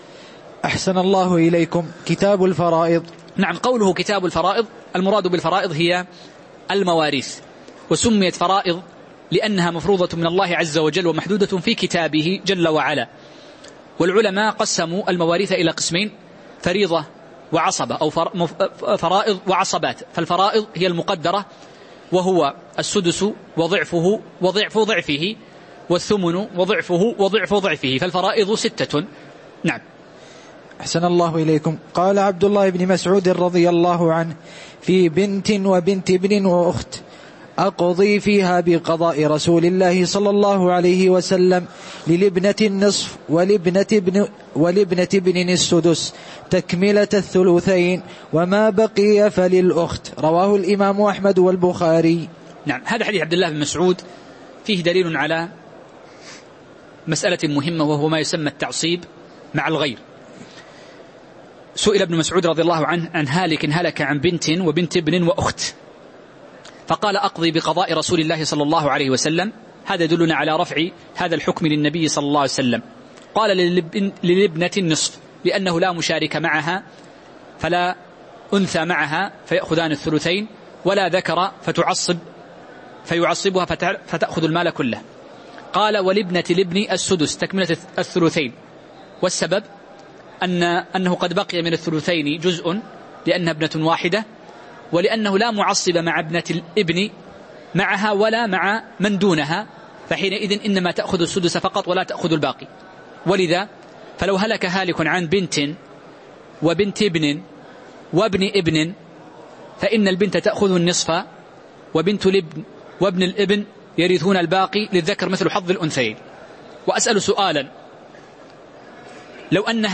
تاريخ النشر ١ شعبان ١٤٤٠ هـ المكان: المسجد النبوي الشيخ